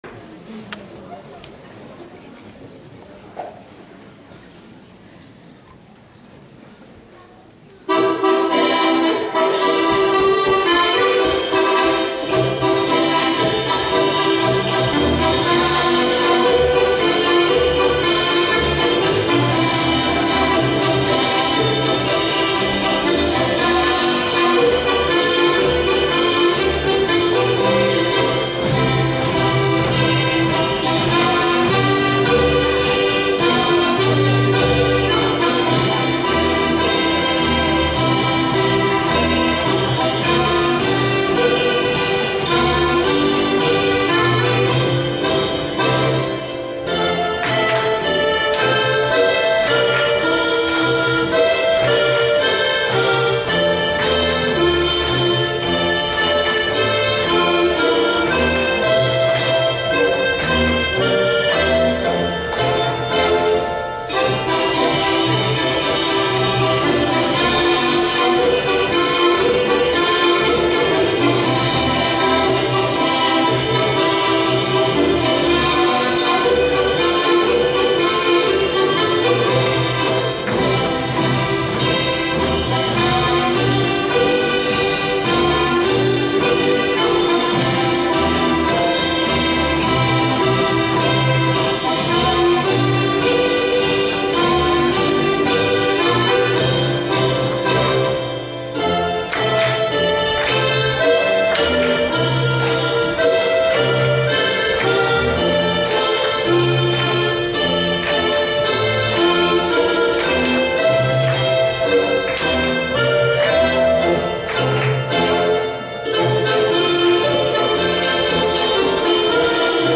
Stern polka                             descrizione